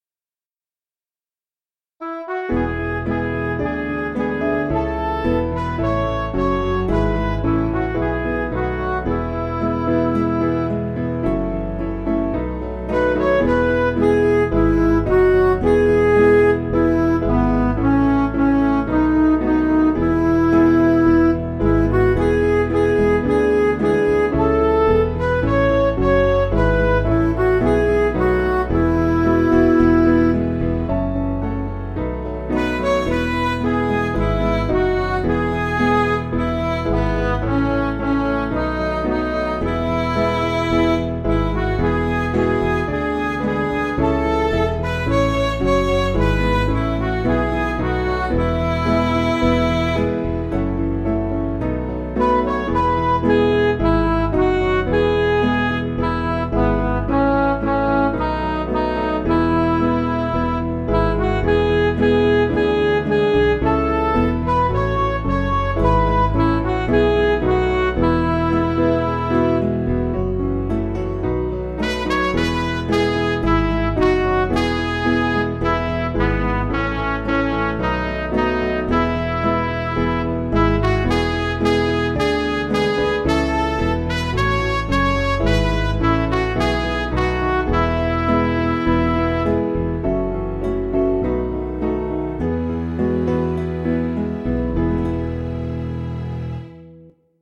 German hymn
Piano & Instrumental